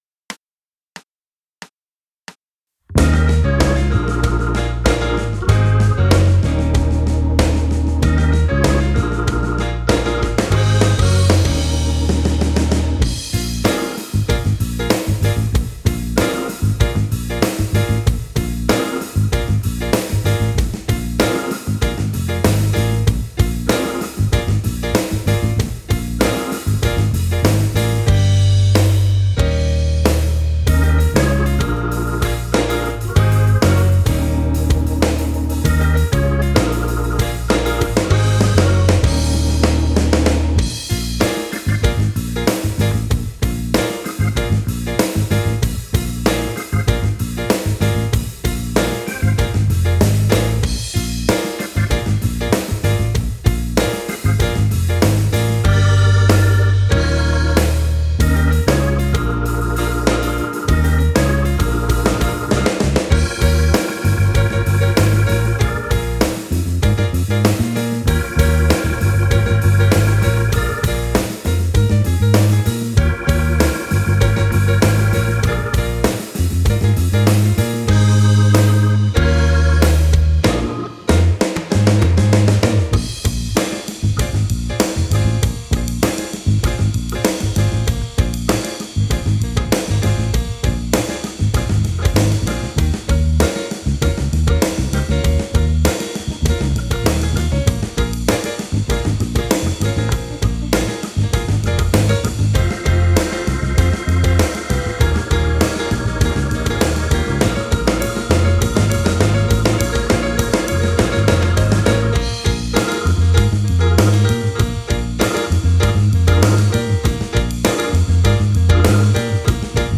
Performance Tracks
in Bbm